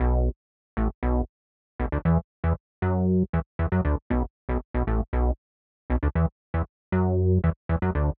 11 Bass PT1.wav